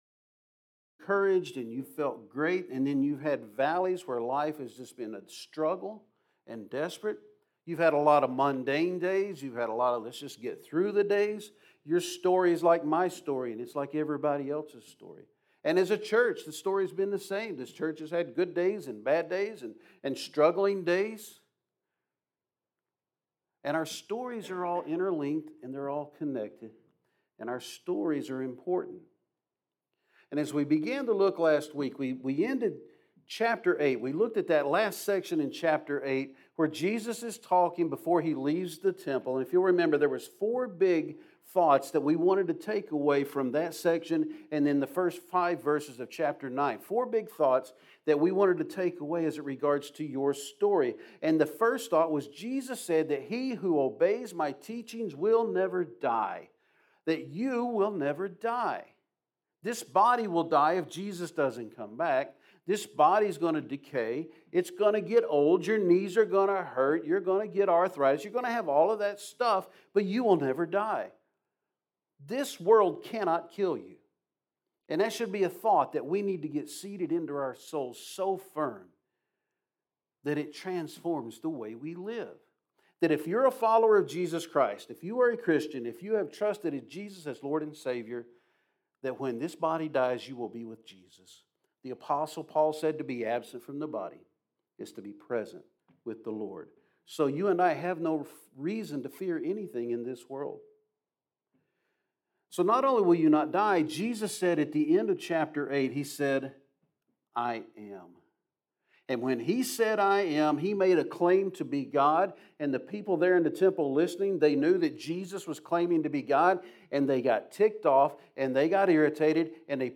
Sermons | Summitville First Baptist Church